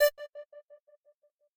synth1_22.ogg